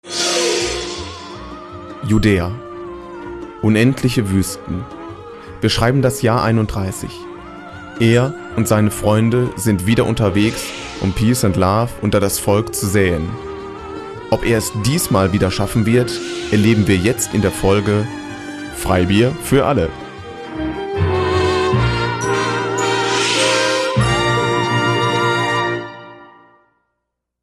Auszüge aus den Hörspielen
Projektarbeit "Hörspiel" - Aufnahmen im Radiostudio